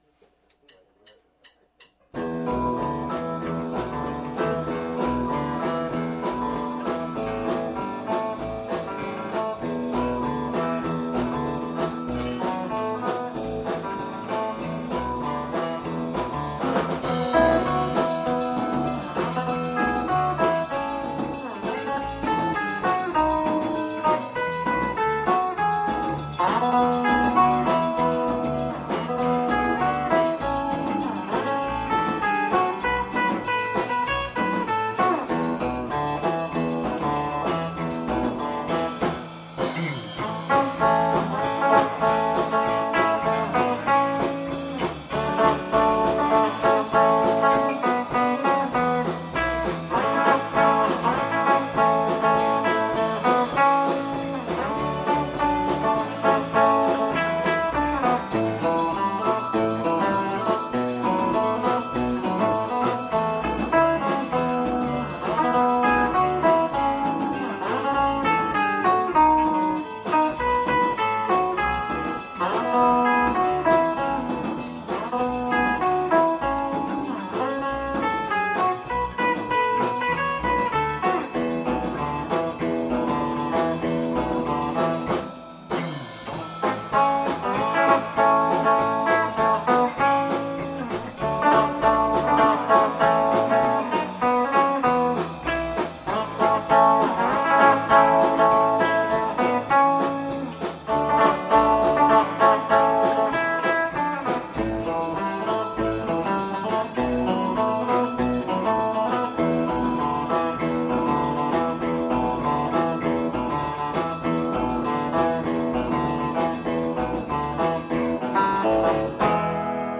19th Live 2015 X’mas Live
場所：ベンチャーズハウス「六絃」
１２月１２日（土）「ベンチャーズハウス六絃」にて「’１５ＸｍａｓＬｉｖｅ」を開催しました、クリスマスソングの演奏や抽選会を行い楽しいライブになりました。